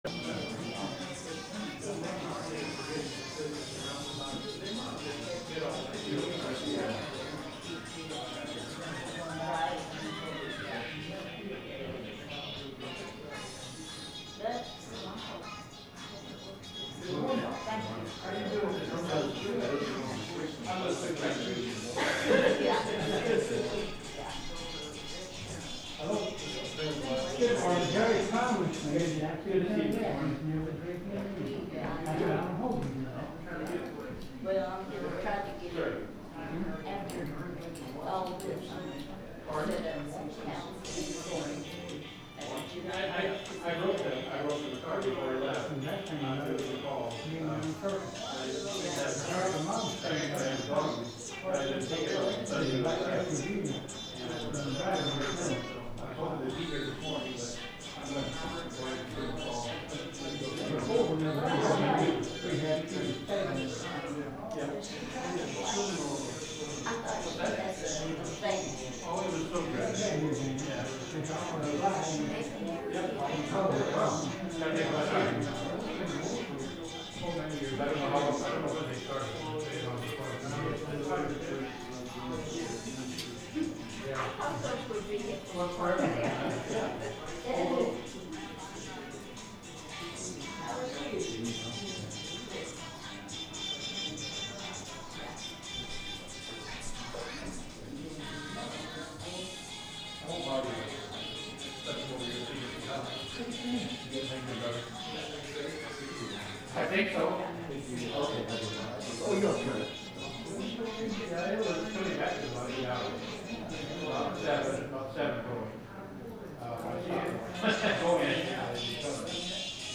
The sermon is from our live stream on 7/27/2025